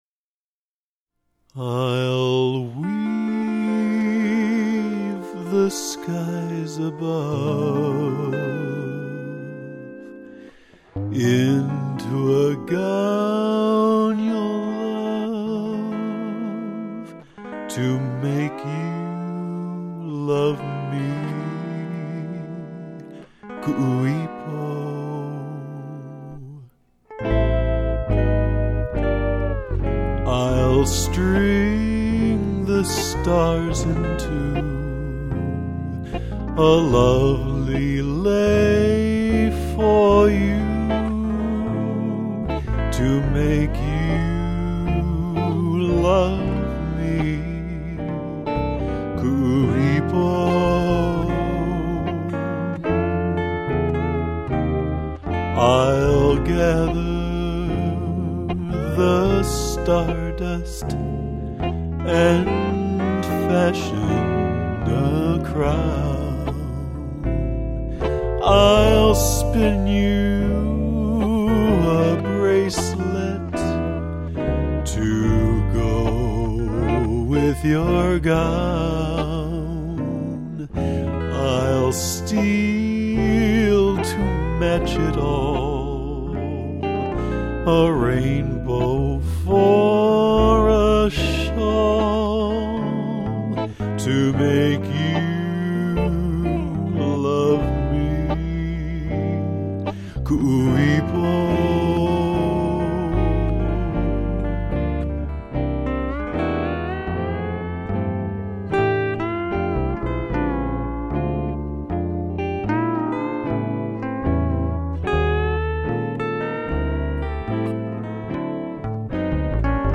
So this is one of the few vocals I was able to squeeze out.